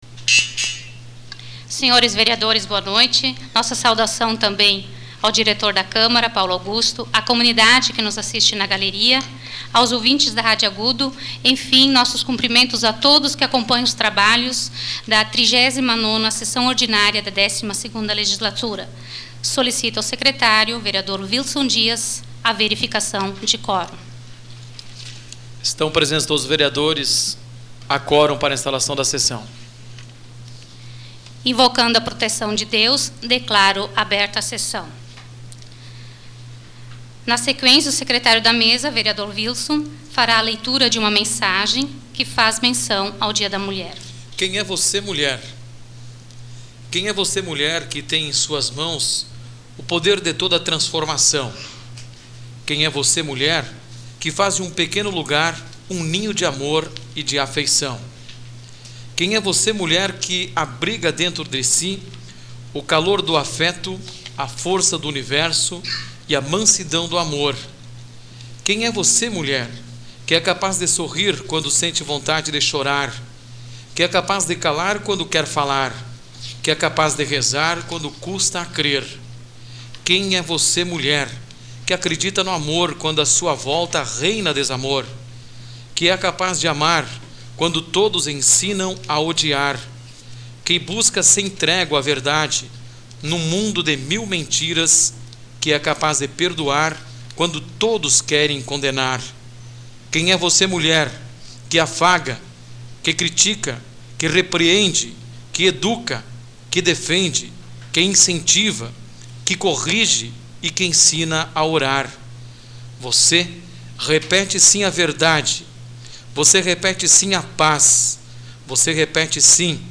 Áudio da 39ª Sessão Plenária Ordinária da 12ª Legislatura, de 06 de março de 2006